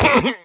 Minecraft Version Minecraft Version snapshot Latest Release | Latest Snapshot snapshot / assets / minecraft / sounds / mob / vindication_illager / death1.ogg Compare With Compare With Latest Release | Latest Snapshot